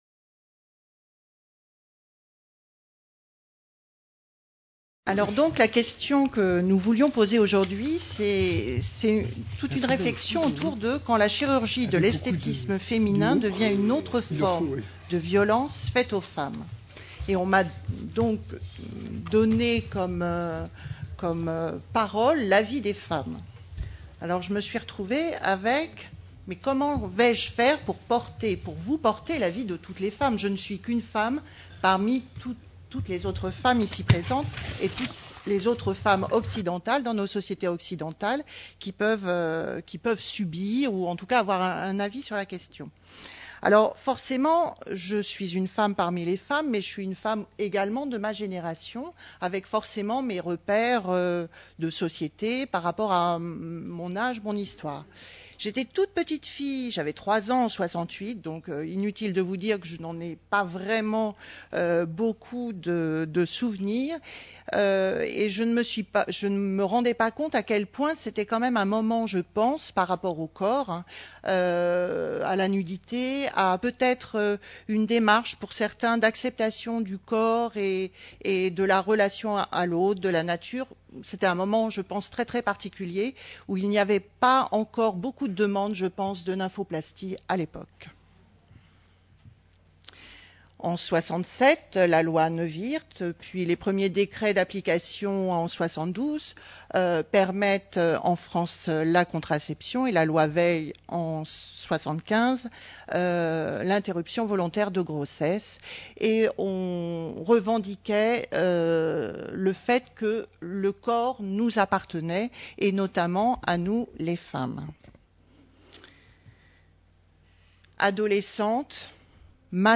4ème Journée Humanitaire sur la Santé des Femmes, organisée par Gynécologie Sans Frontières, le 29 novembre 2013, au Palais du Luxembourg (Paris). De la culture traditionnelle à la mode, quand la chirurgie devient une autre forme de violence faite aux femmes.